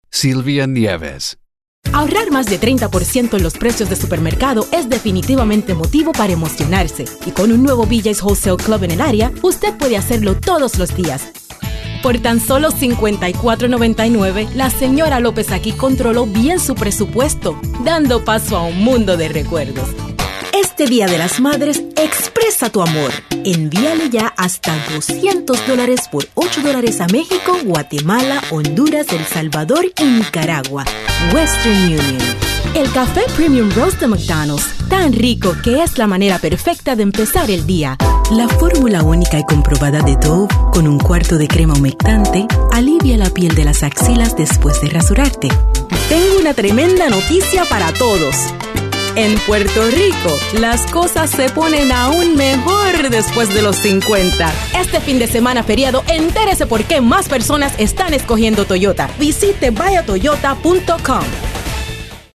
Sprechprobe: Werbung (Muttersprache):
Girl next door,fun,warm,sophisticated,sexy